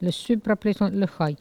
Il crie pour appeler le chien ( prononcer le cri )
Saint-Jean-de-Monts